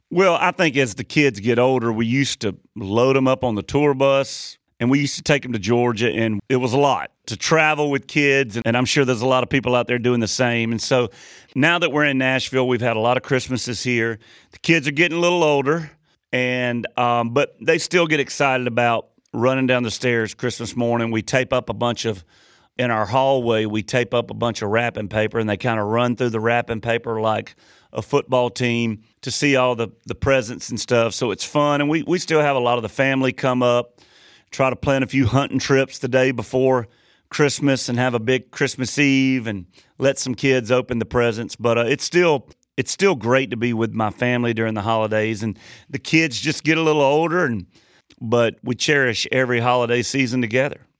Audio / As his kids get a little older, Luke Bryan talks about what happens at Christmas at the Bryan household in Nashville.